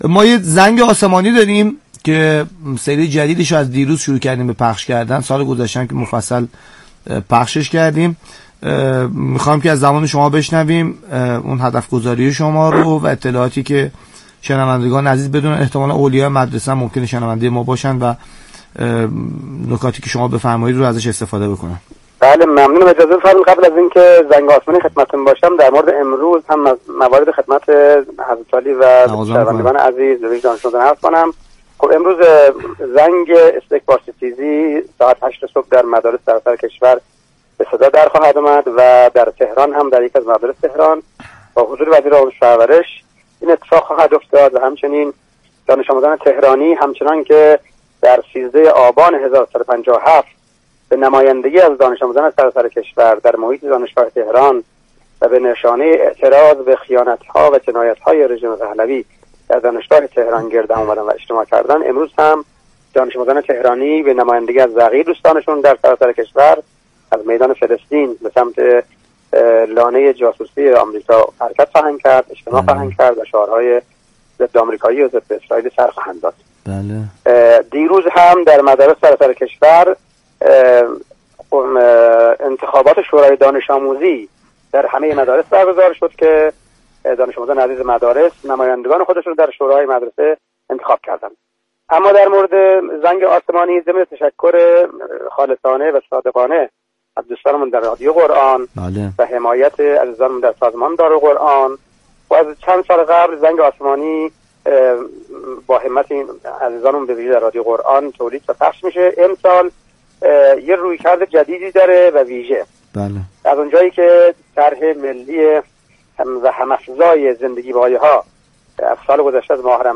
مدیرکل قرآن، عترت و نماز وزارت آموزش و پرورش با حضور در برنامه «طلیعه» رادیو قرآن از آغاز فصل جدید برنامه «زنگ آسمانی» در مدارس خبر داد.